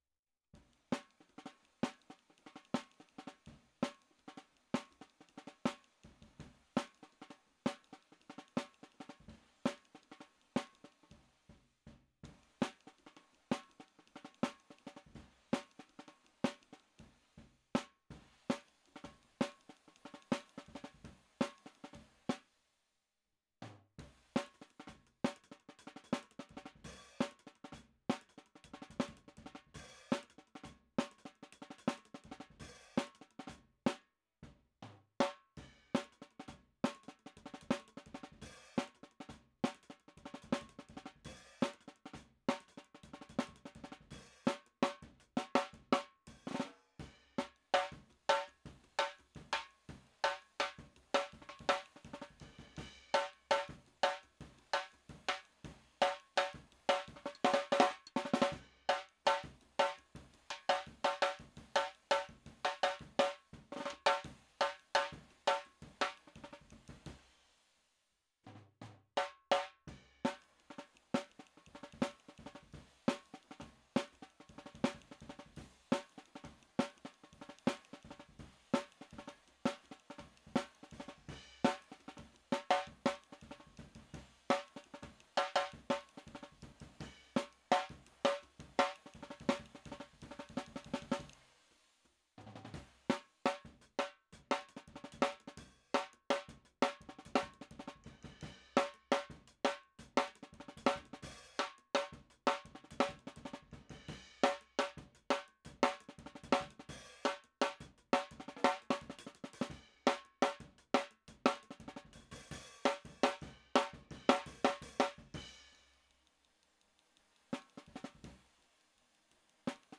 Index of /4 DRUM N BASS:JUNGLE BEATS/BEATS OF THE JUNGLE THAT ARE ANTIFUNGAL!!/RAW MULTITRACKS
SNARE_1.wav